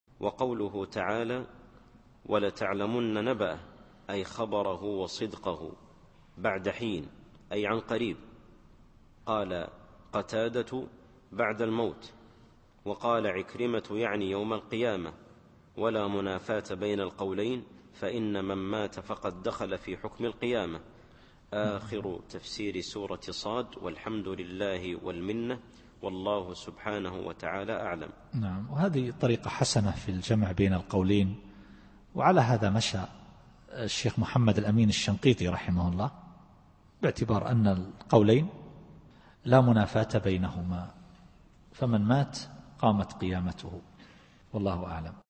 التفسير الصوتي [ص / 88]